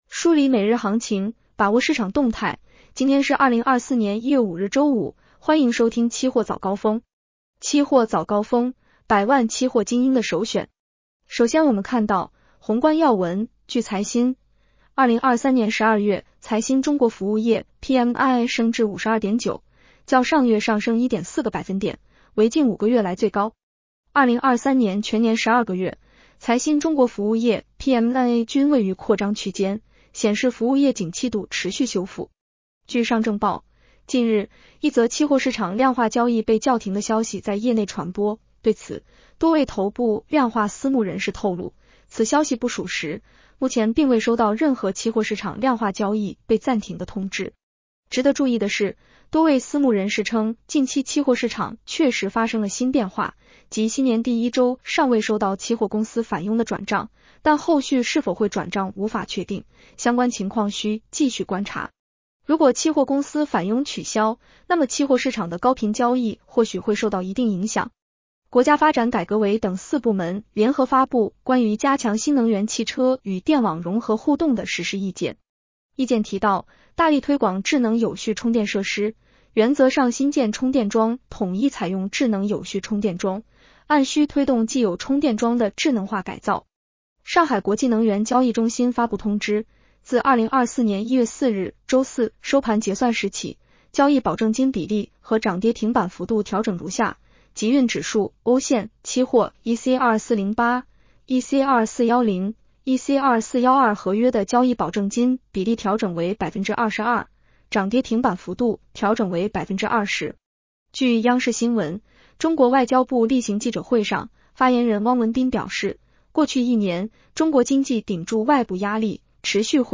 【期货早高峰-音频版】 女声普通话版 下载mp3 宏观要闻 1.